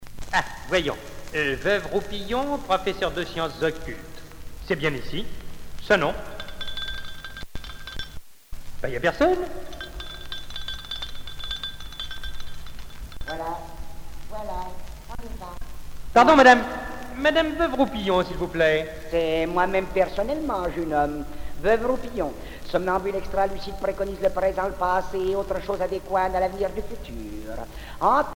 Genre sketch